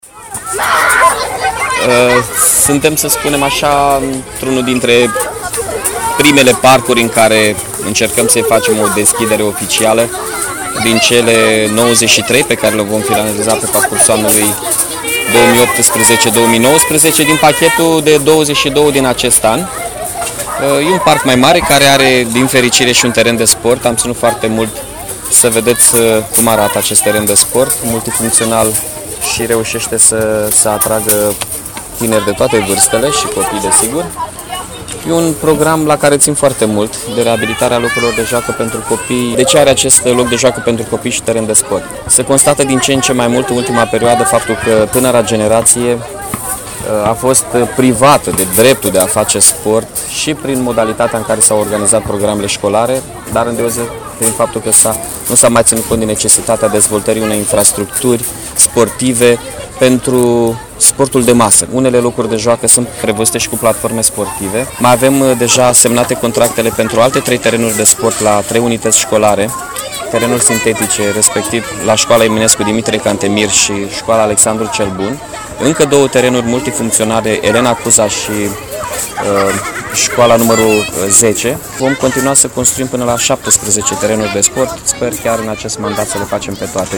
Primarul municipiului Iași, Mihai Chirica